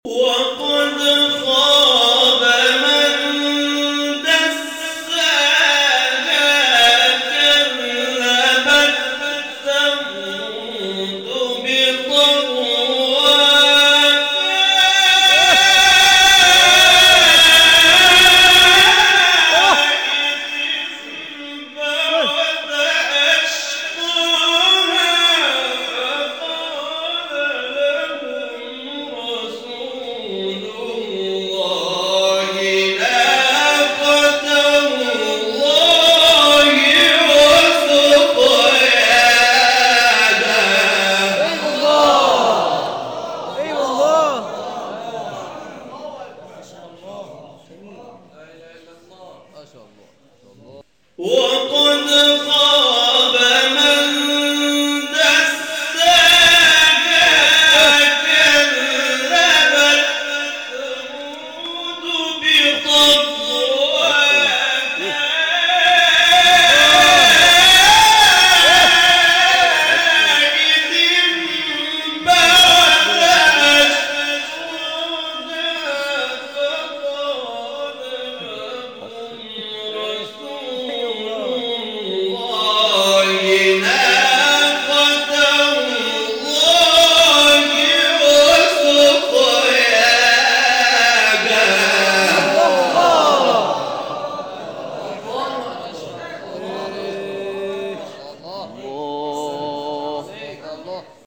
گروه شبکه اجتماعی: مقاطعی صوتی از تلاوت قاریان ممتاز کشور ارائه می‌شود.